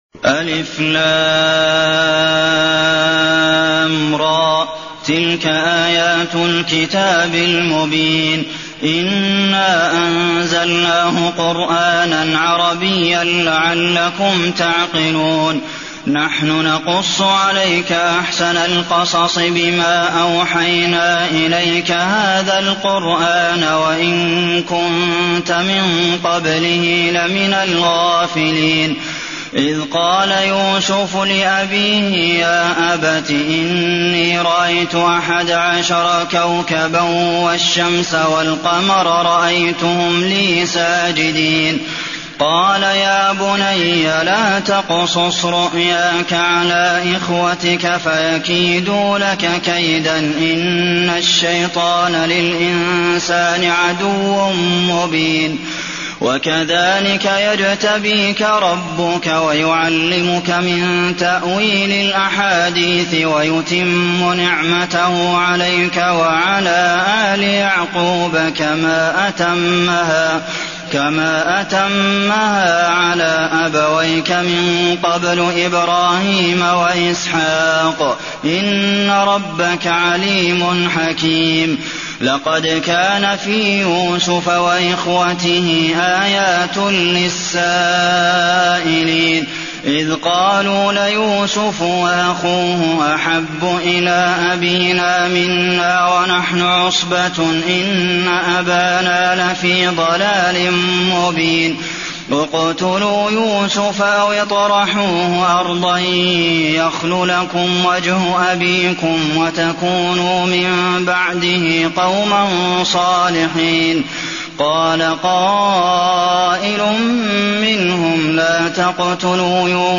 المكان: المسجد النبوي يوسف